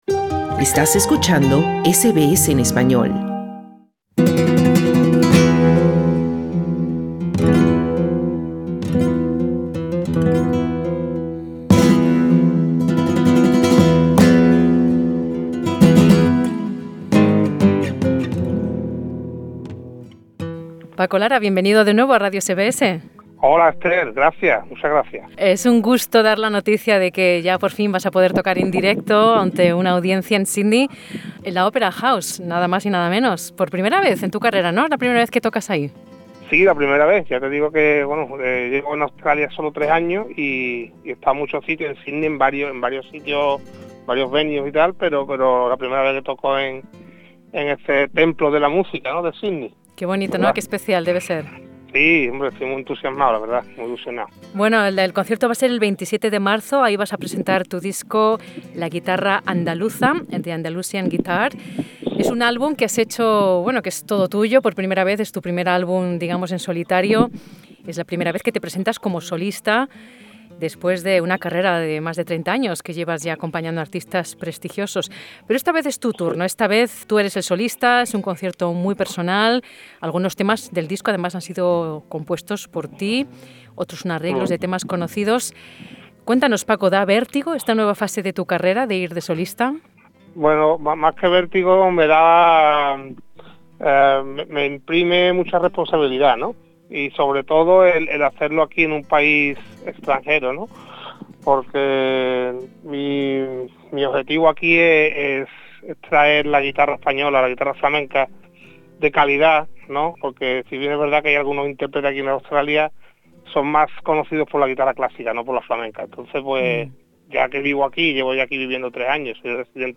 Escucha el podcast con la entrevista haciendo clic en la imagen principal.